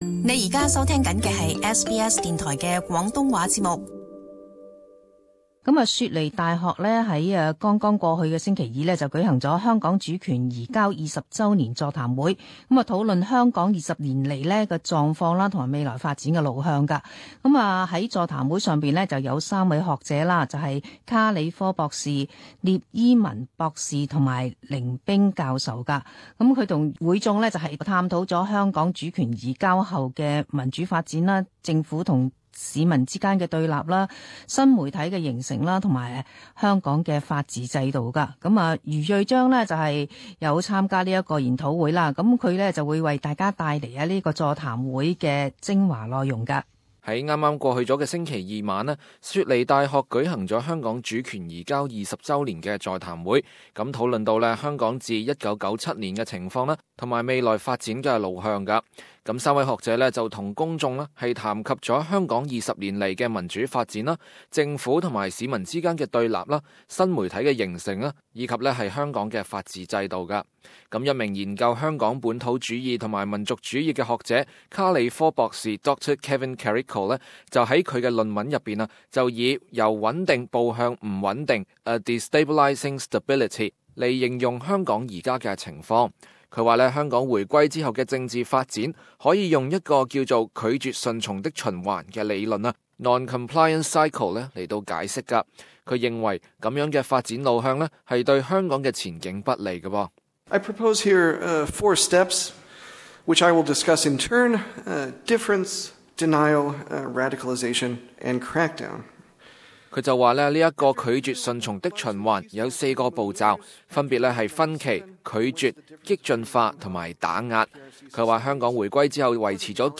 雪梨大學在剛過去的星期二，舉行了香港主權移交二十週年座談會，討論香港二十年來的狀況及未來發展的路向。